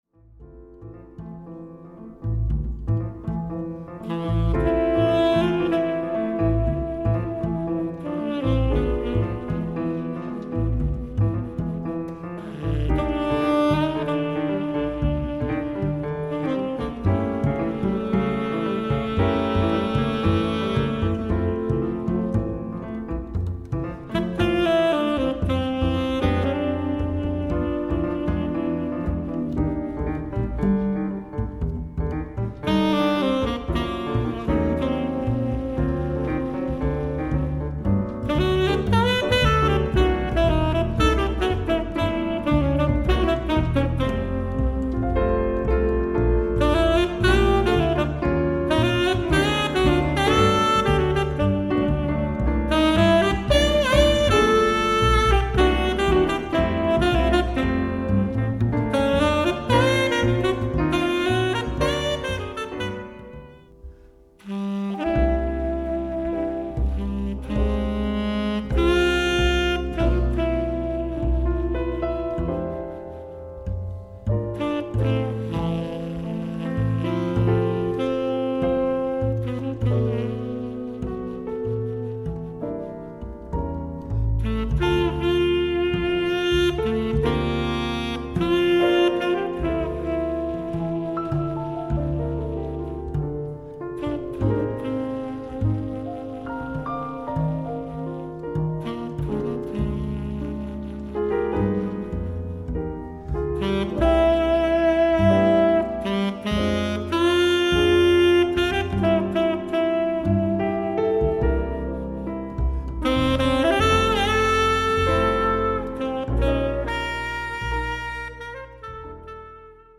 素材: シリコンラバー
音色のと特徴 ：　VIよりも少し明るい音色、ダーク、渋い、吹きやすい
スタイル ：ジャズ
Tenor